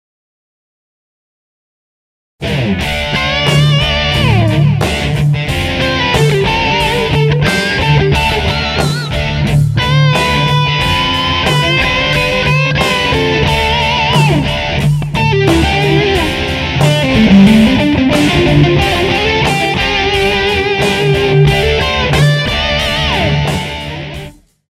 Vytahování a stahování tónů na kytaře
Stahování a vytahování strun
Tato technika se často spojuje se zpracováním tónu - vibrátem.
Natahovat i stahovat můžete od čtvrttónů až kam vám technika dovolí.